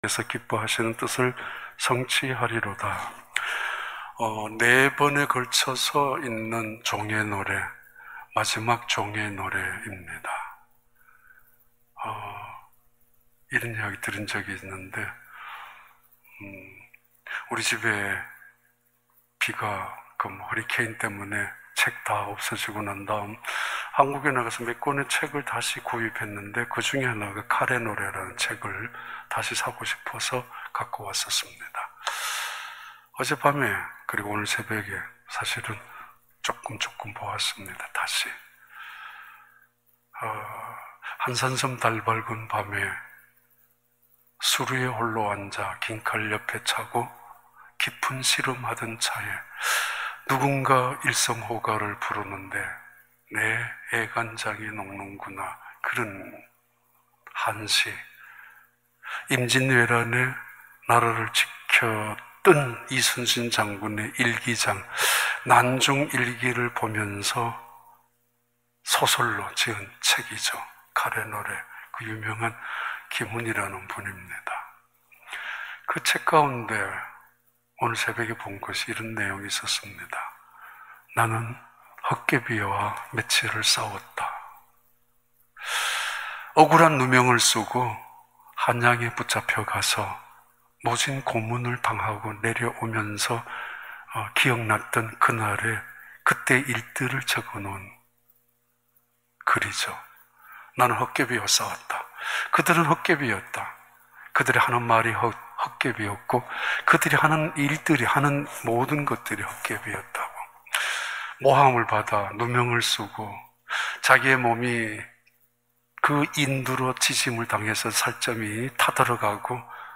2021년 4월 3일 특별 새벽 예배10.mp3